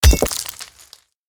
axe-mining-ore-8.ogg